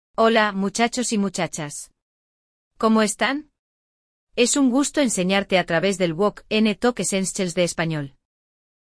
Na nossa história de hoje vamos falar um pouco sobre isso a partir de um diálogo entre duas amigas que estão curtindo o entardecer no parque de diversões. Além de expandir seu vocabulário sobre esse tema, você vai aprender alguns nomes de comidas, expressões e praticar os verbos em espanhol.